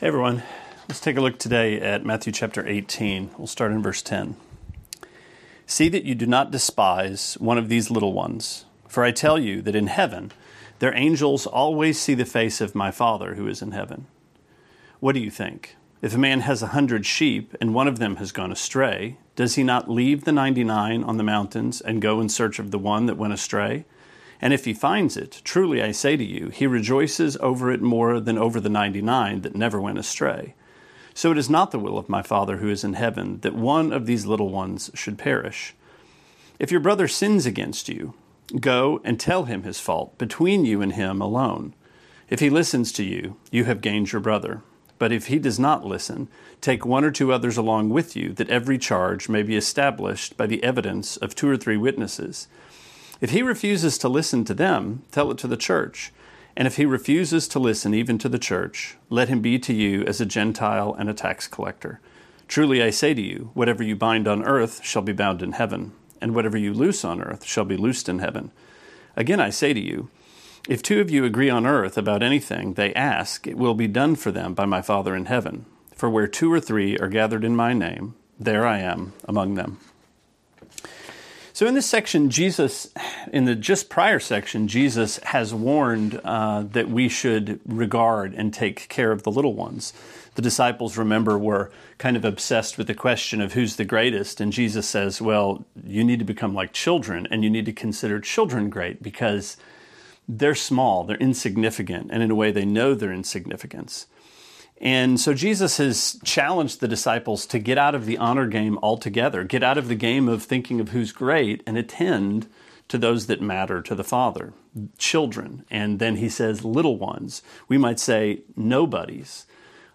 Sermonette 3/11: Matthew 18:10-20: Love that Confronts